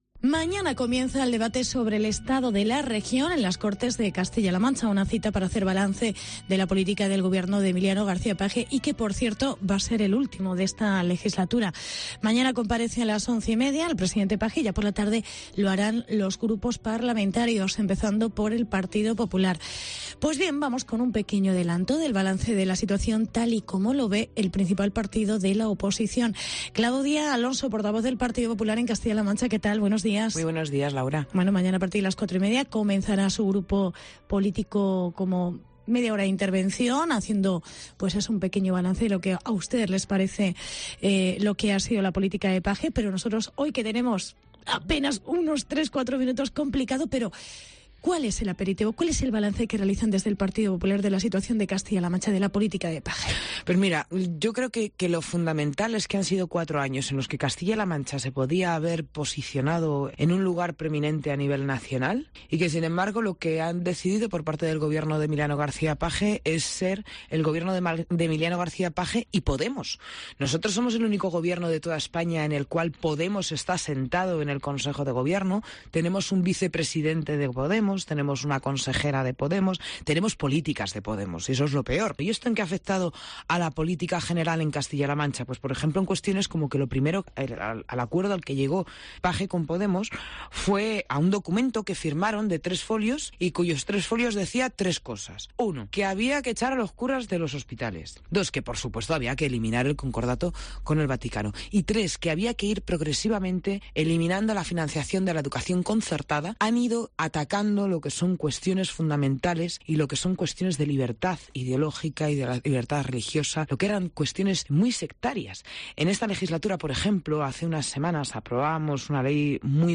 Entrevista con la portavoz del PP en CLM, Claudia Alonso, en vísperas del Debate de la Región